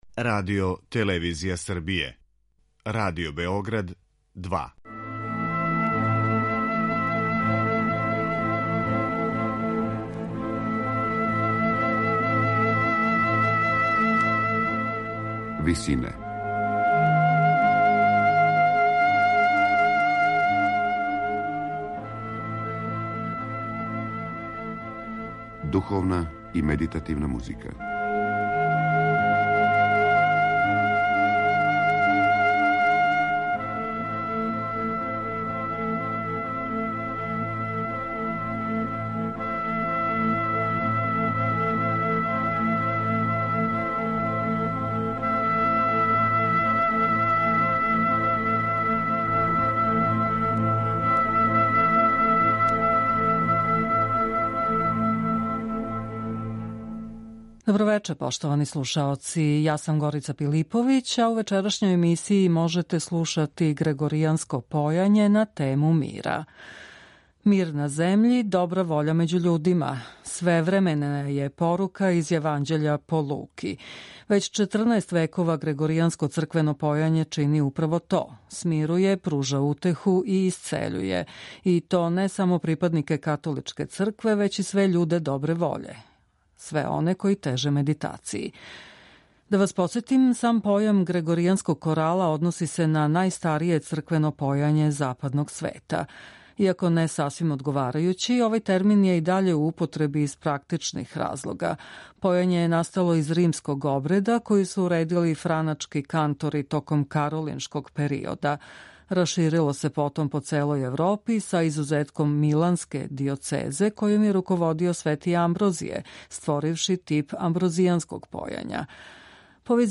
На Бадње вече по важећем календару, у емисији Висине од 19.13 можете слушати грегоријанске напеве на тему мира.
Већ 14 векова грегоријанско црквено појање чини управо то - смирује, пружа утеху и исцељује, и то не само припаднике Католичке цркве већ и све људе добре воље, све оне који теже медитацији. Сам појам грегоријанског корала односи се на најважније црквено појање Западног света.